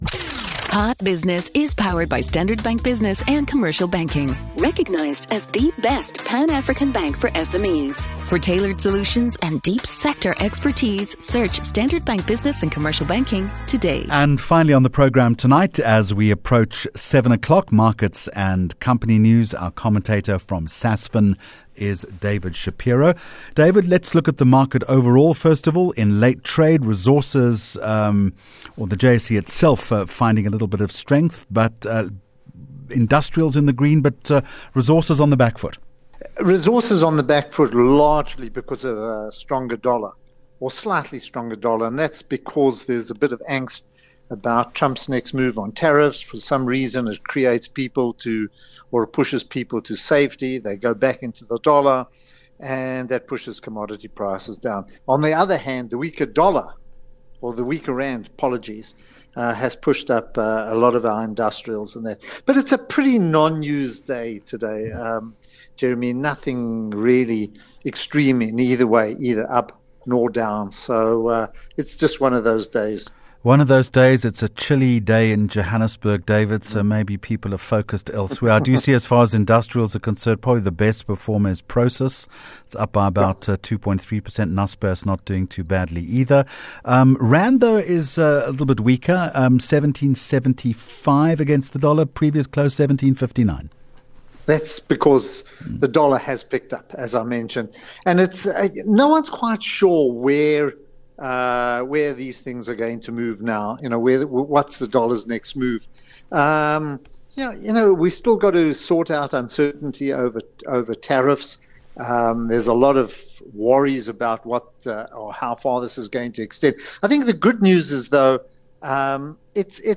7 Jul Hot Business Interview